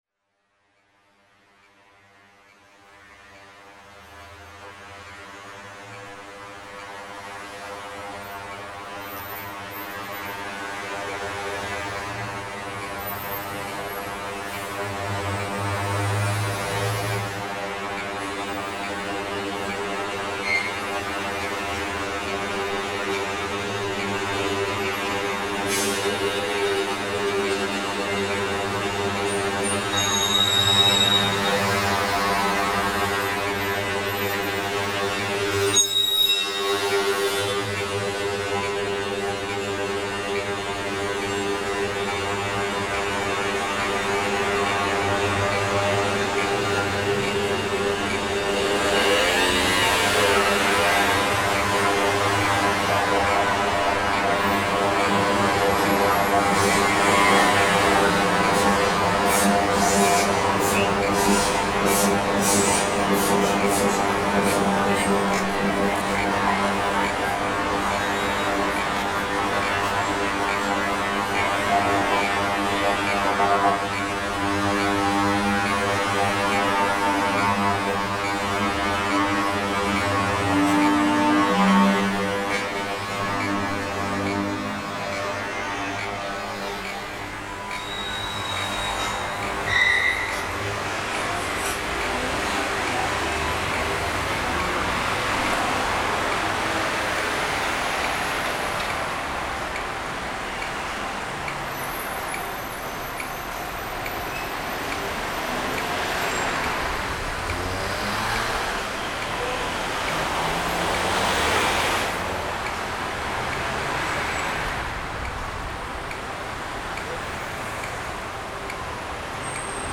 electric bass
consists of original sound recorded in Berlin and Warsaw